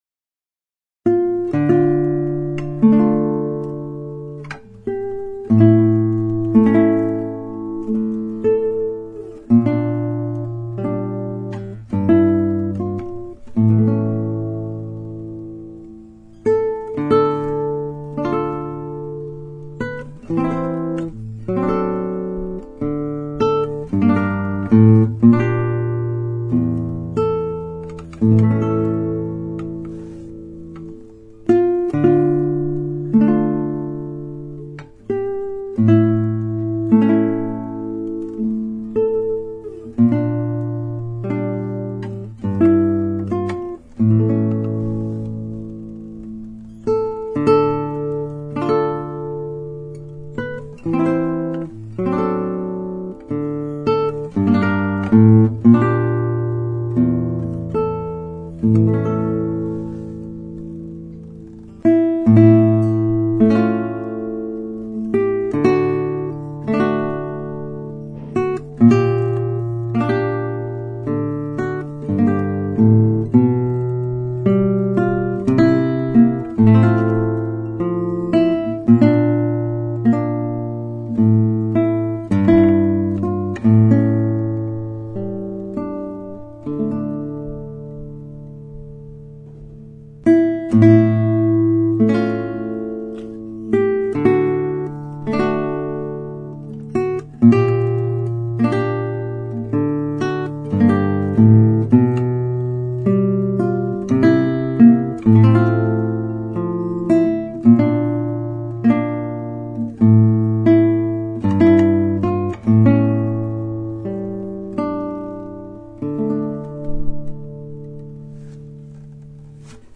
Classical Guitar